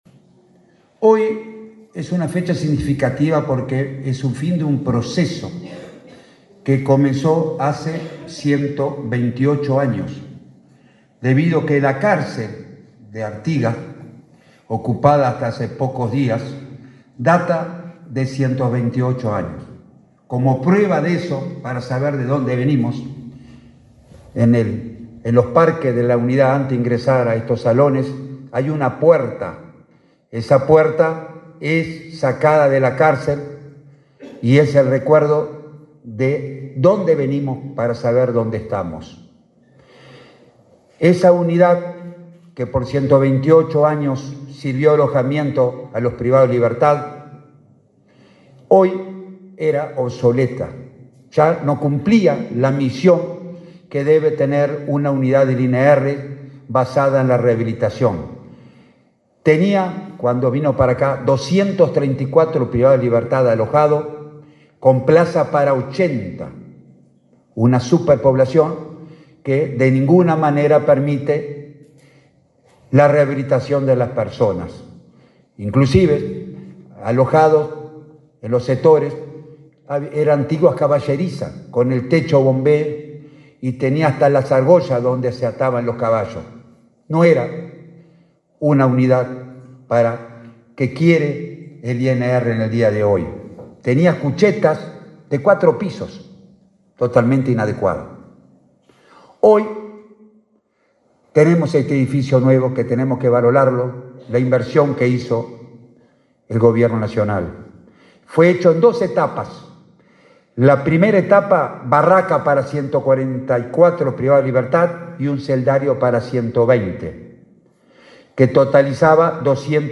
Palabras de autoridades del Ministerio del Interior en Artigas